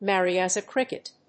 アクセント(as) mérry [chírpy, lívely] as a crícket